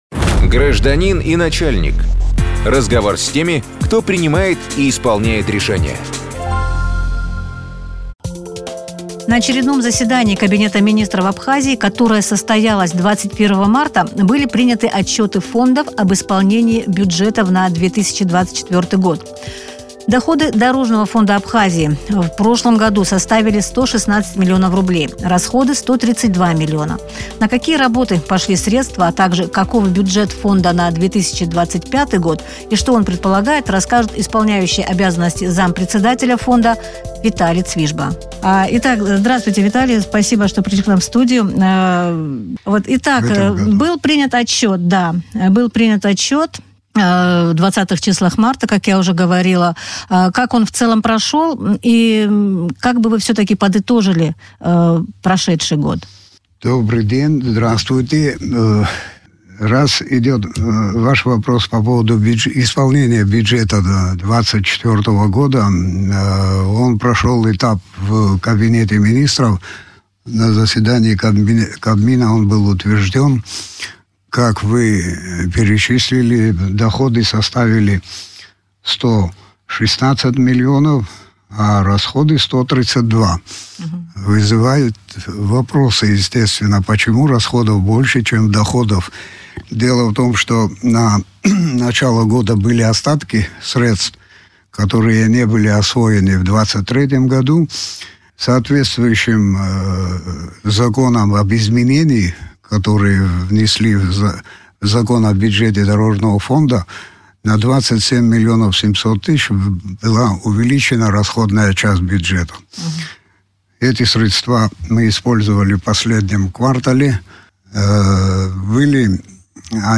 Как использовались эти средства, каков бюджет Фонда на 2025 год, что он предполагает, в эфире радио Sputnik рассказал зампредседателя ведомства Виталий Цвижба.